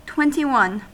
Ääntäminen
Vaihtoehtoiset kirjoitusmuodot twentyone twenty one Ääntäminen US : IPA : [ˌtwɛn.ti.ˈwʌn] Haettu sana löytyi näillä lähdekielillä: englanti Käännöksiä ei löytynyt valitulle kohdekielelle.